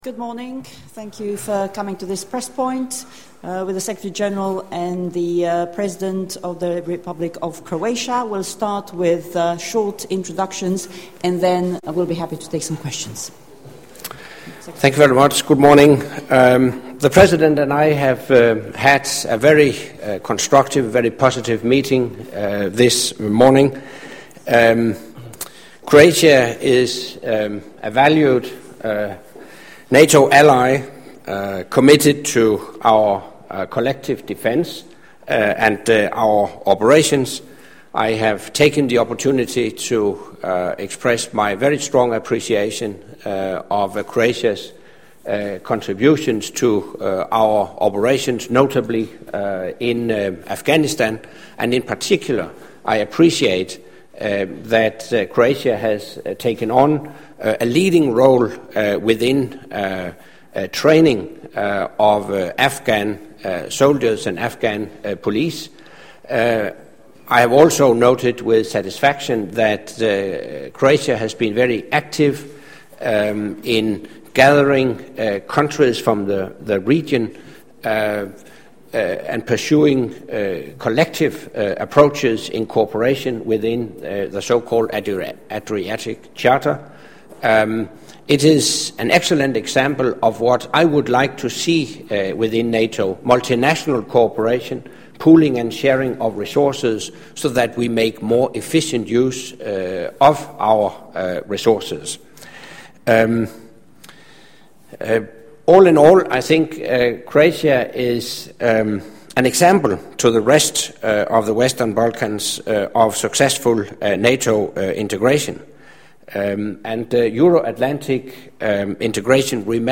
Joint press point with NATO Secretary General Anders Fogh Rasmussen and the President of the Republic of Croatia, Mr. Ivo Josipovic